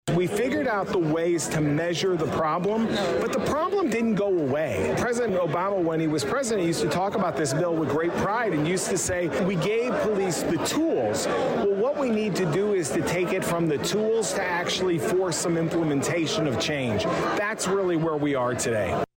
A passionate crowd attended Saturday’s (March 4th) speech